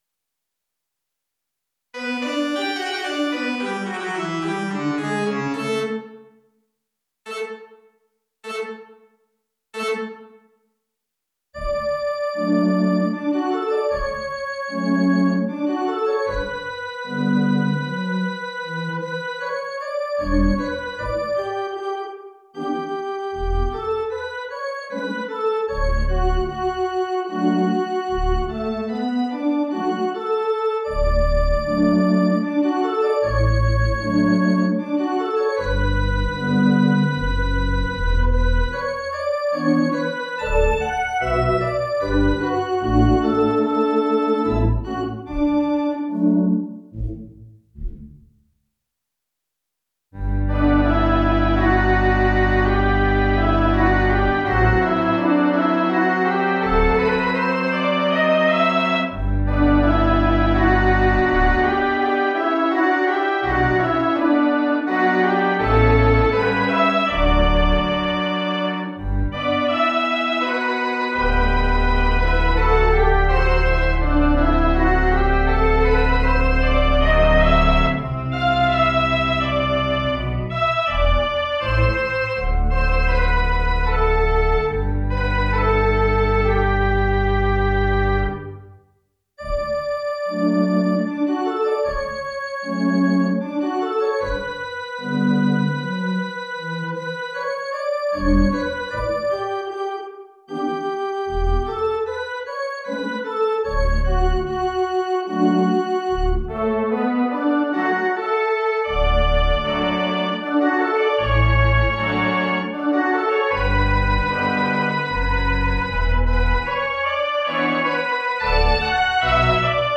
- played using virtual pipe organ instruments.
All of the pieces presented on this page used freely available virtual pipe organs.
File originally sequenced for 8 instruments.
Recorded using Miditzer 216 virtual Wurlitzer 216 pipe organ.